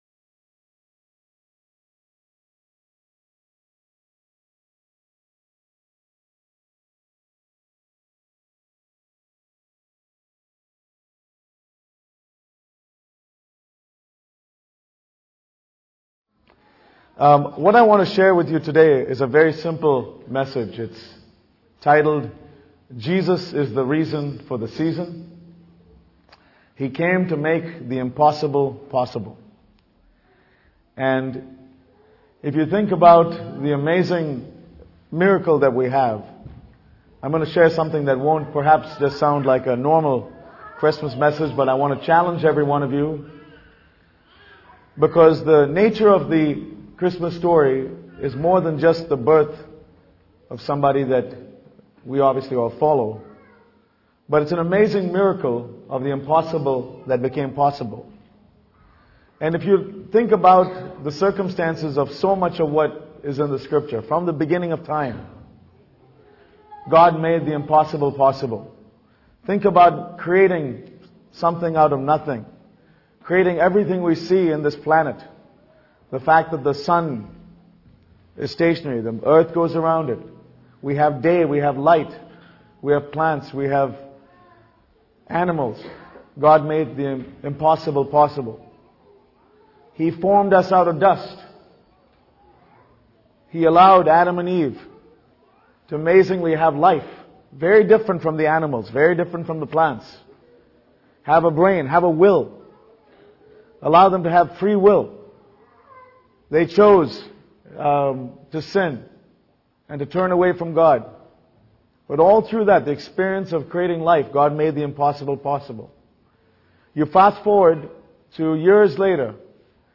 Jesus Came To Make The Impossible Possible Speaker : Sanjay Poonen